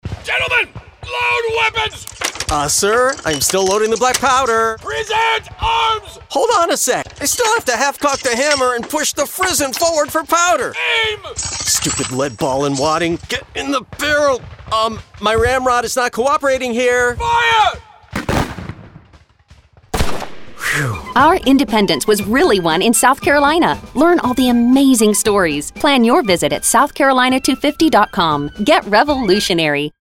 Radio
SC250-Musket.mp3